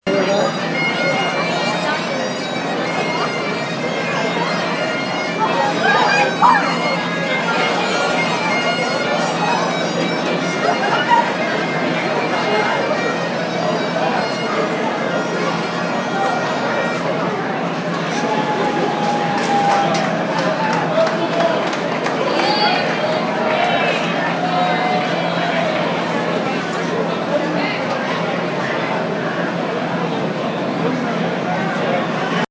Field Recording
St. Patrick’s Day, Track 19, someone playing bagpipes, people talking, people clapping, drunk people.